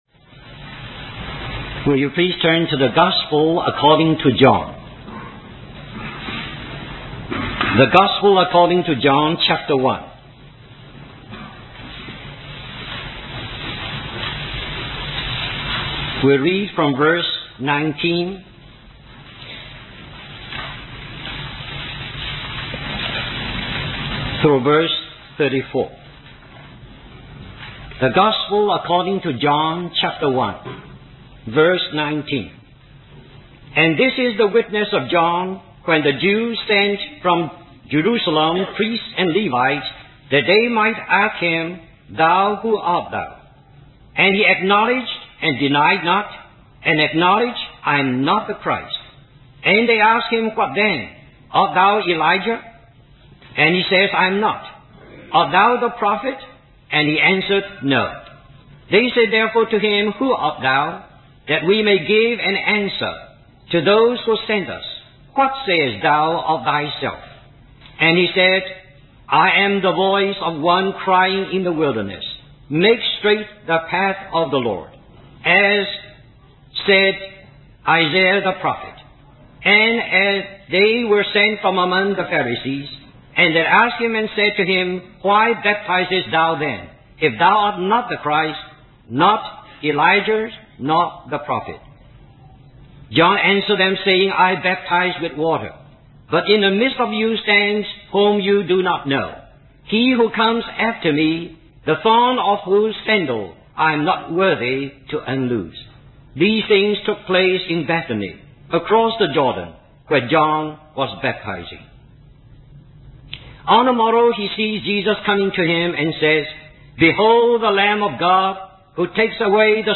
In this sermon, a missionary who had spent many years in a foreign land requested five minutes to speak on a subject.